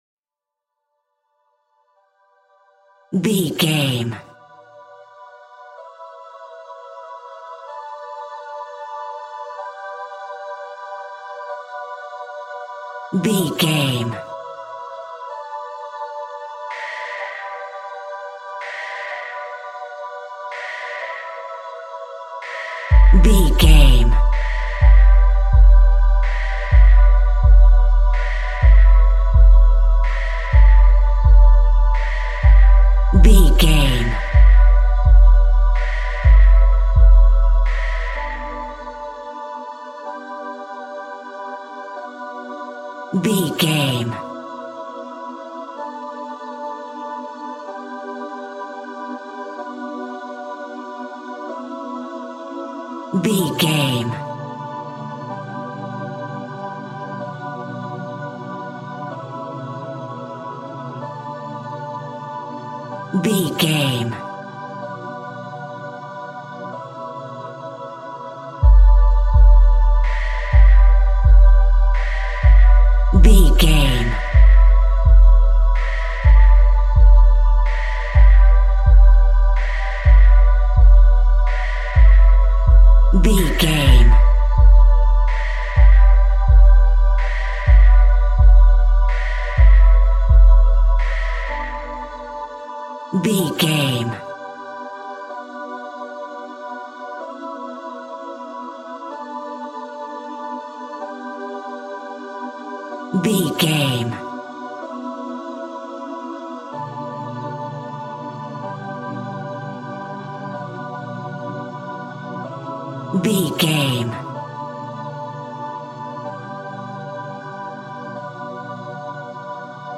Cool and relaxing loopable music written for synthesizer
Ionian/Major
D
Slow
disturbing
haunting
magical
mystical
suspense
hypnotic
intense
synthesiser